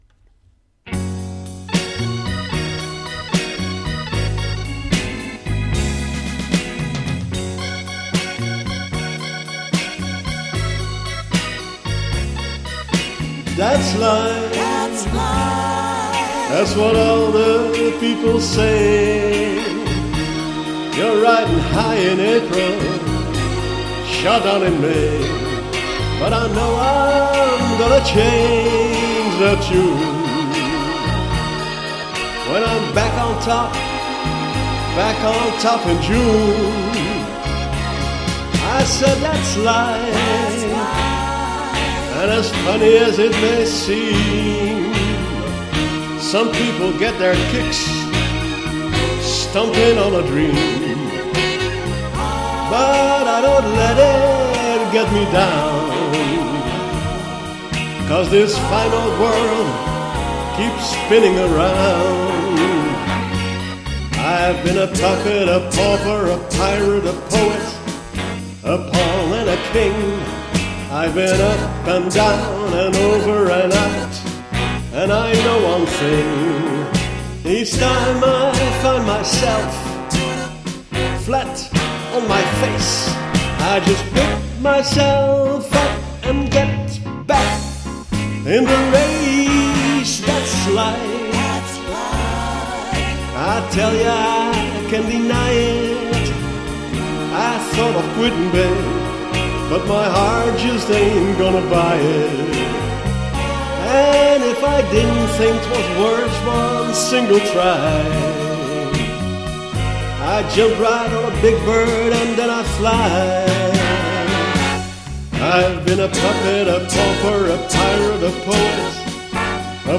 sung by me :-)